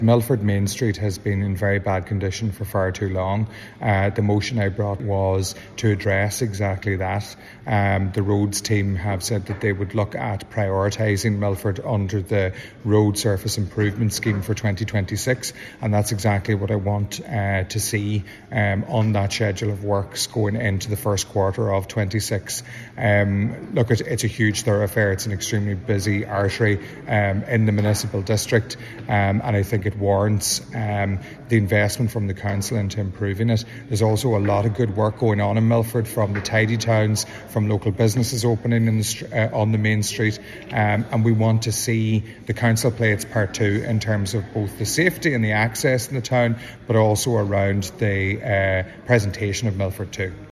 Donegal County Council has agreed to prioritise works in next year’s programme of works which Councillor Meehan has welcomed: